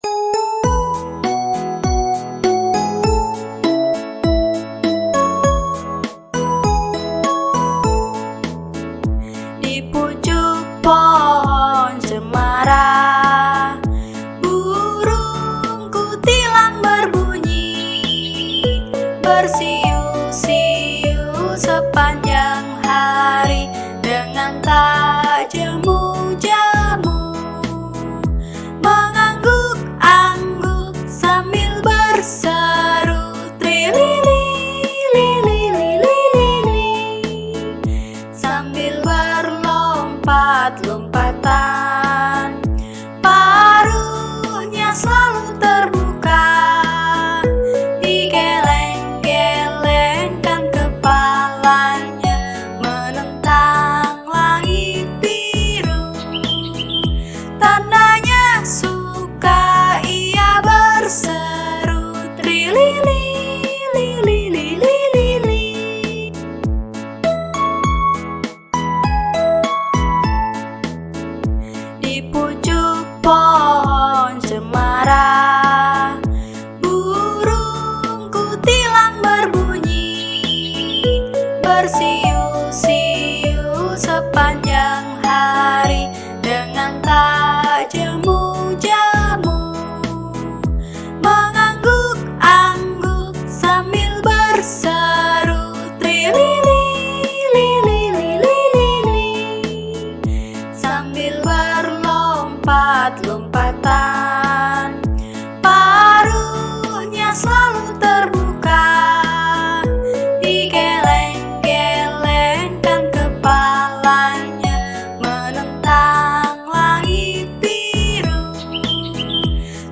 Children Song
Skor Angklung